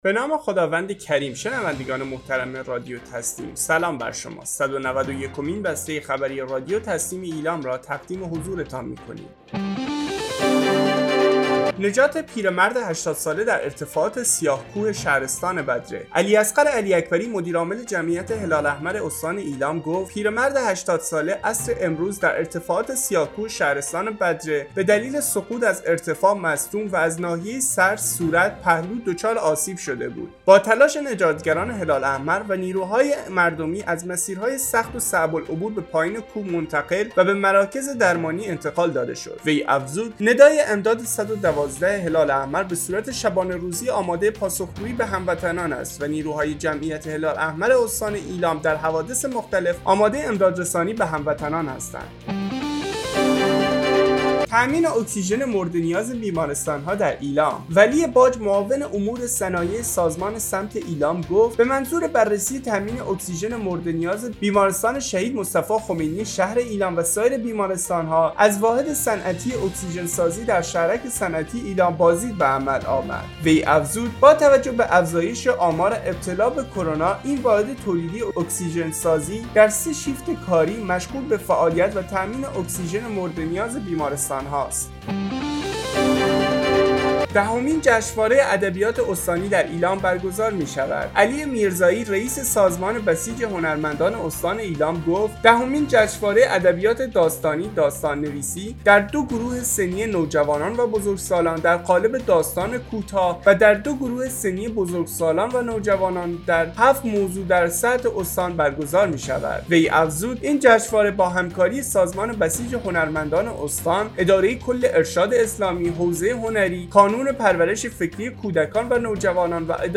گروه استان‌ها - آخرین و مهمترین اخبار استان ایلام در قالب بسته خبری